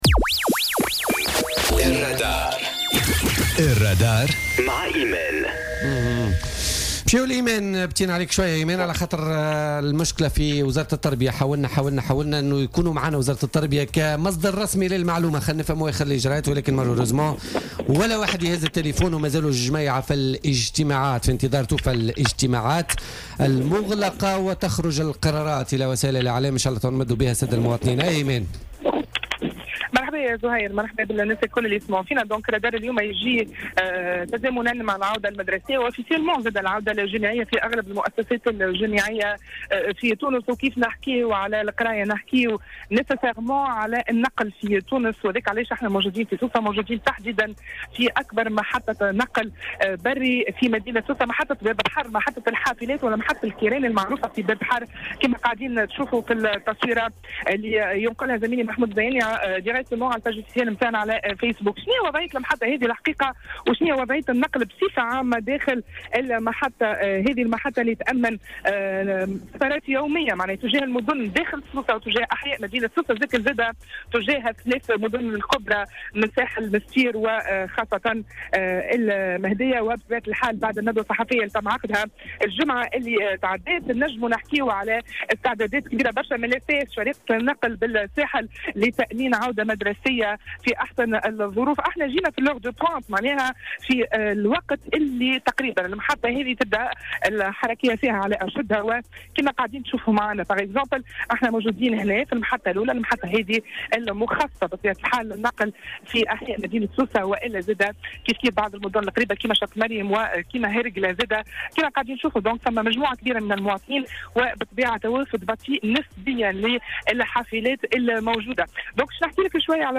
تنقّل فريق "الرادار" اليوم الاثنين 18 سبتمبر 2017، إلى محطة الحافلات بباب بحر، للاطلاع على وضعية الحافلات ومدى توفرها تزامنا مع العودة المدرسية والجامعية.